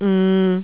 m4